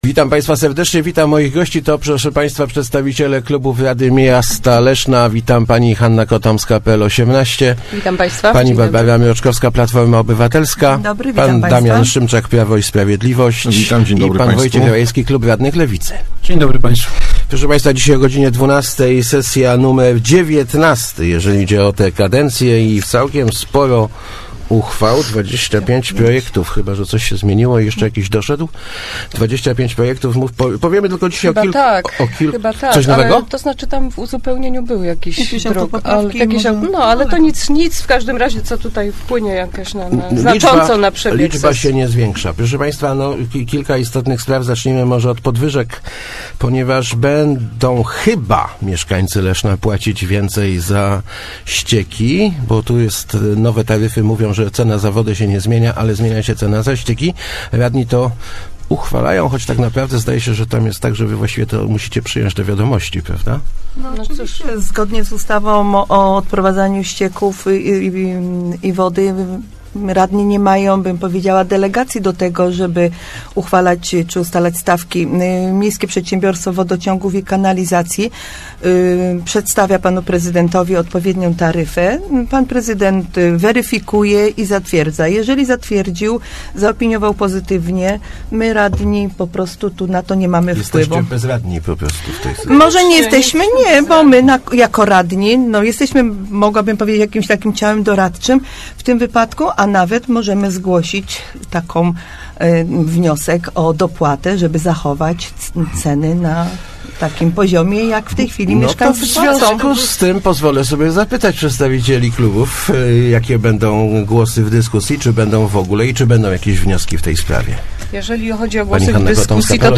- B�dzie to z korzy�ci� dla uczni�w - twierdz� radni i jak zapowiedzieli obecni w studiu, Barbara Mroczkowska z PO, Hanna Kotomska z PL 18, Damian Szymczak z PiS-u i Wojciech Rajewski z Lewicy, zag�osuj� za przyj�ciem tej uchwa�y.